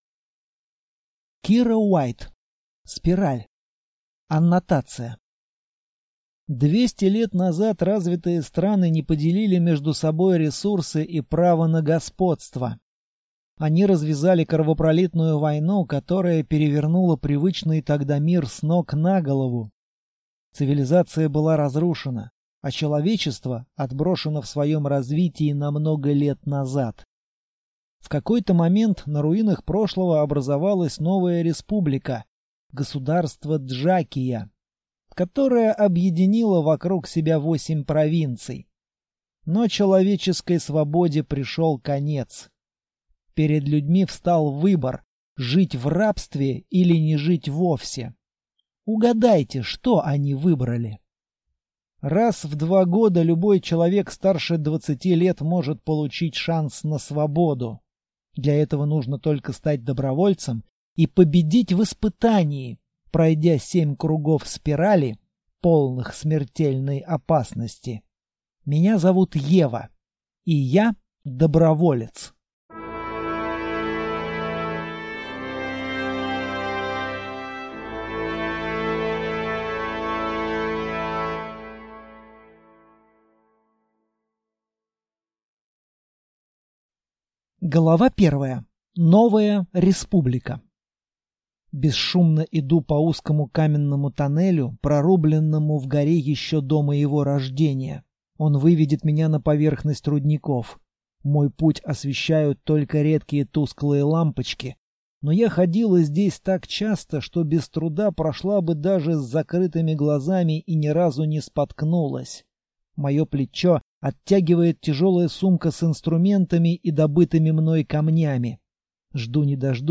Аудиокнига Спираль | Библиотека аудиокниг